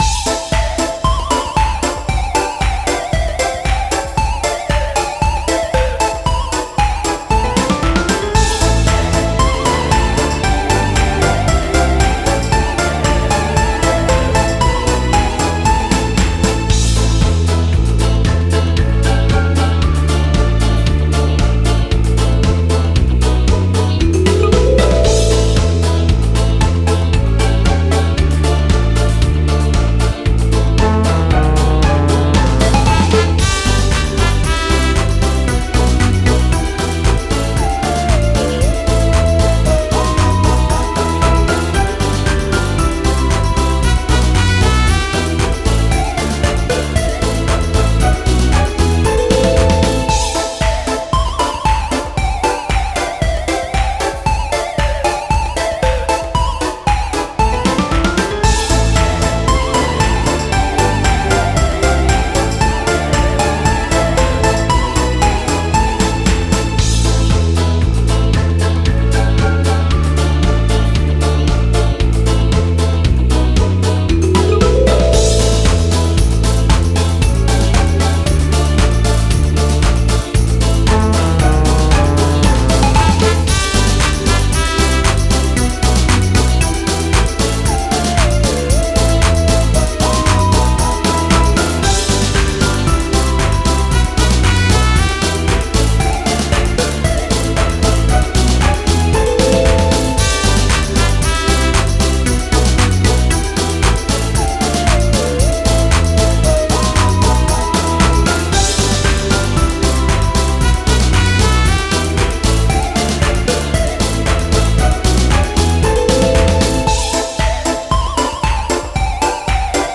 минус к песне